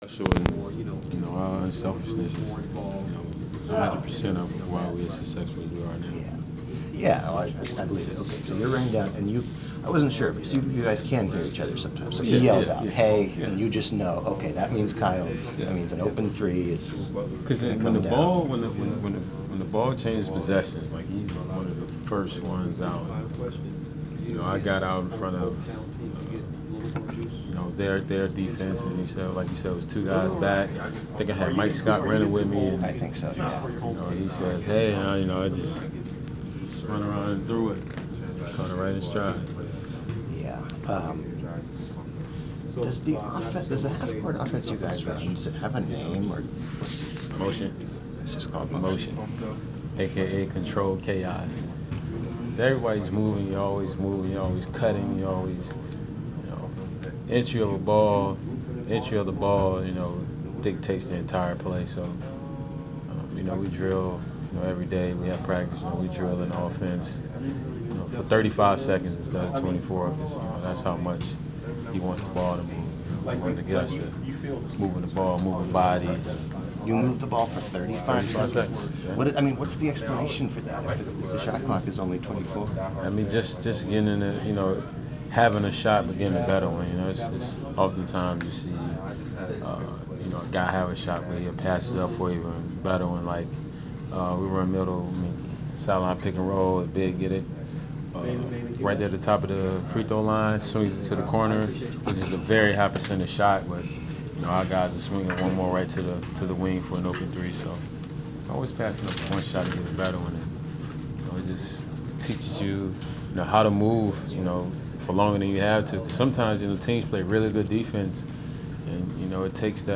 Inside the Inquirer: Pregame interview with Atlanta Hawks’ Kent Bazemore (1/11/15)